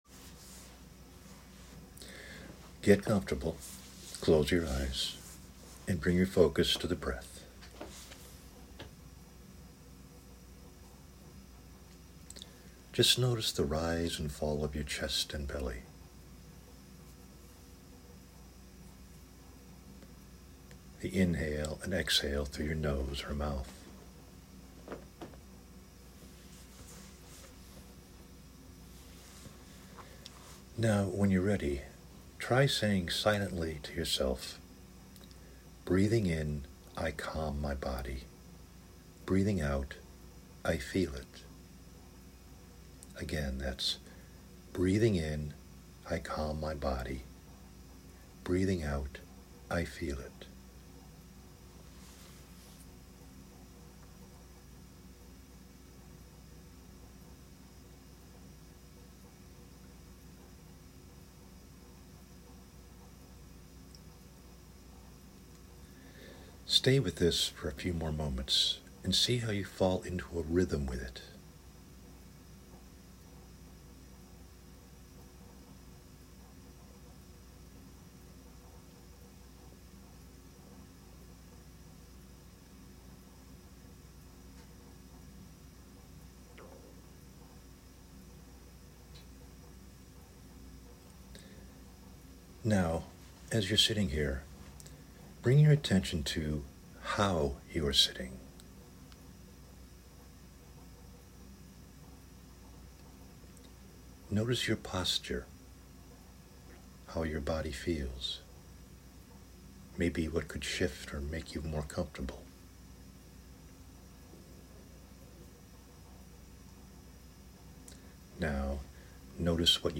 Guided exercises are especially beneficial with the initial learning; yet they’re also quite helpful in learning how to develop, extend, and expand your skills in SQ.
meditation, mindfulness, reconditioning